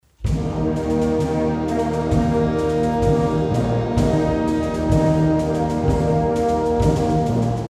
Audiobeispiel eines Waldhornsatzes
Audiobeispiel Hornsatz
hornsatz.mp3